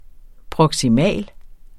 Udtale [ pʁʌgsiˈmæˀl ]